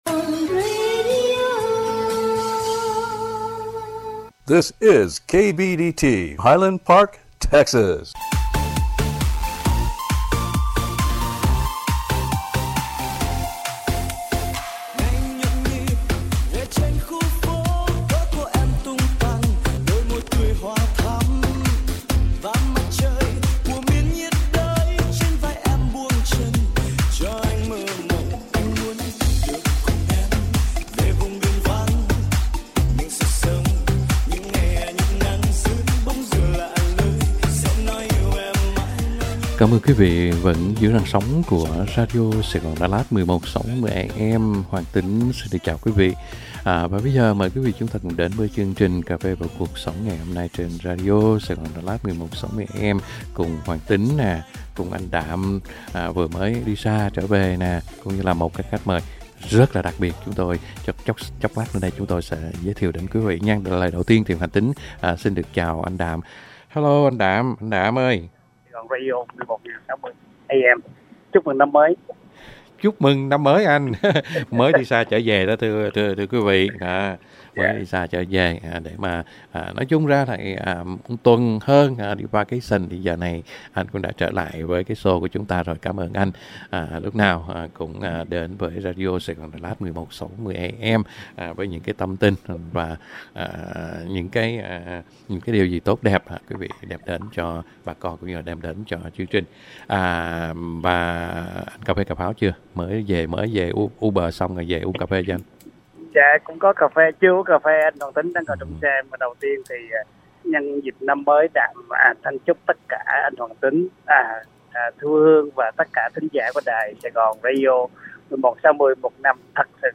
Cà phê & cuộc sống:Talk show